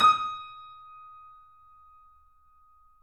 Index of /90_sSampleCDs/E-MU Producer Series Vol. 5 – 3-D Audio Collection/3DSprints/3DYamahaPianoHyb